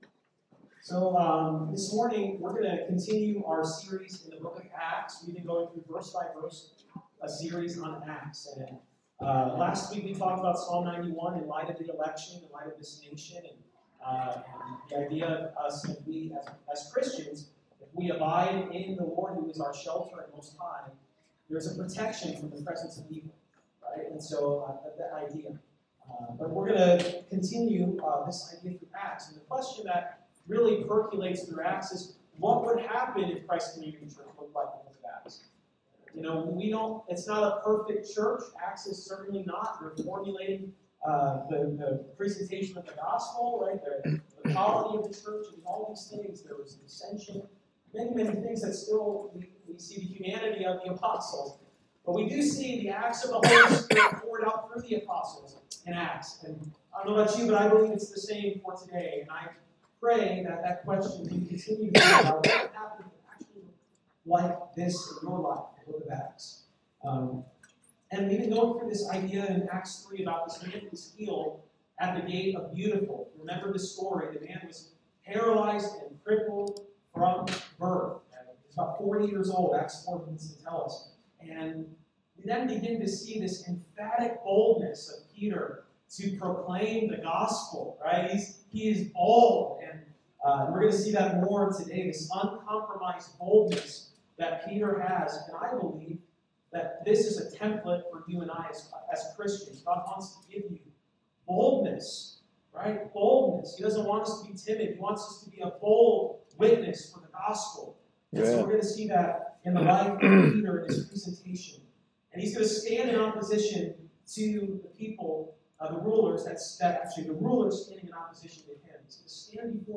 Acts 4:1-22 Service Type: Sunday Morning Acts chapter 4.